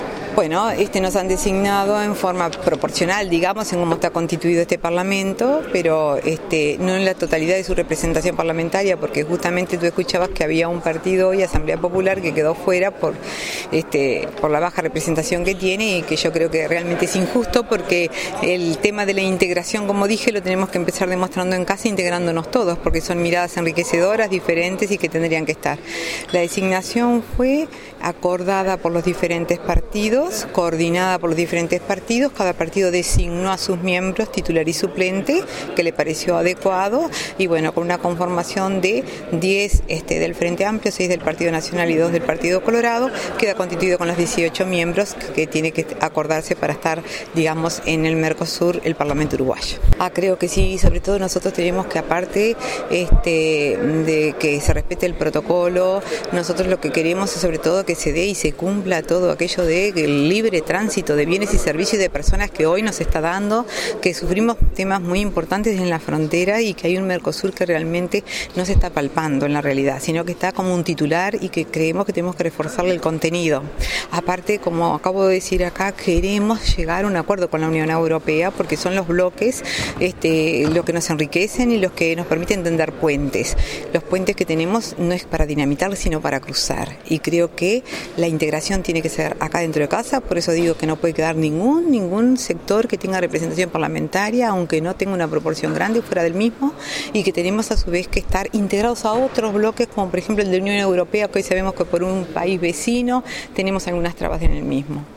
senadora-marta-montaner.mp3